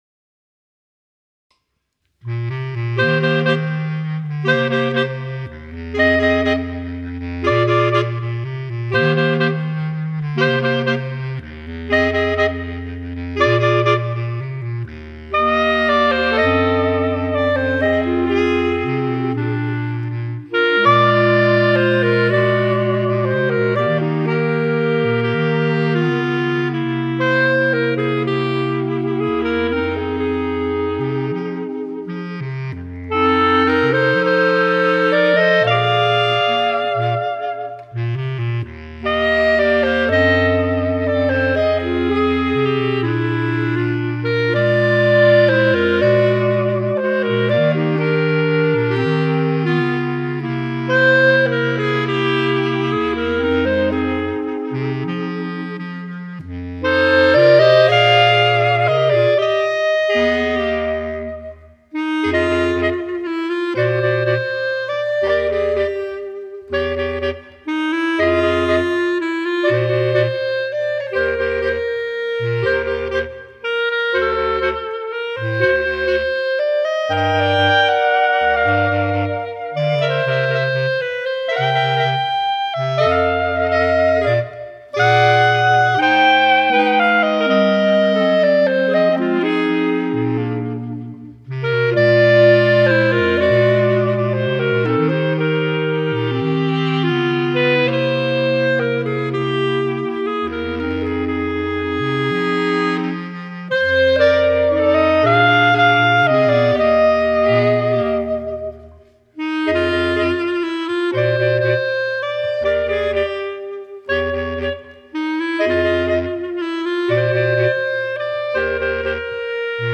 Bb Clarinet Range: E1 to Bb3. Bass Clarinet Lowest Note: E1.